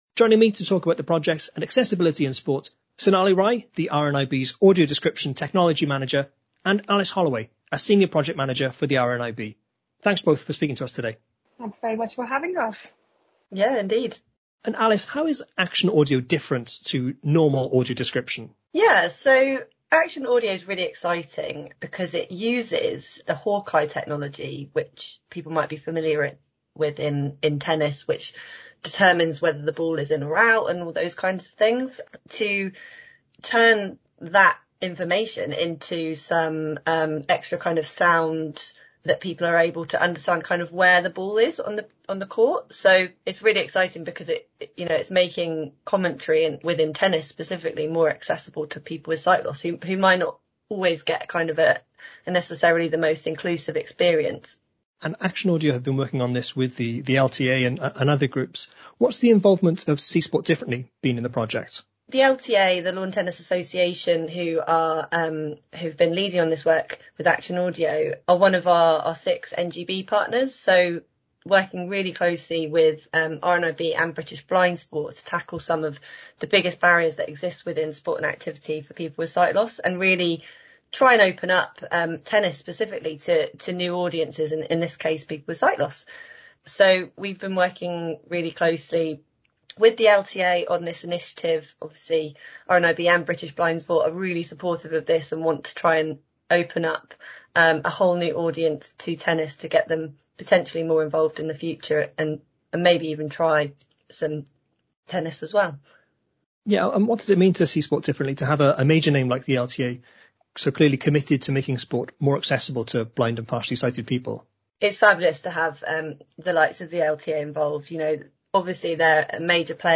Download - S2 Ep656: Chat With Neil Fachie & Matthew Rotherham at Paralympics GB House | Podbean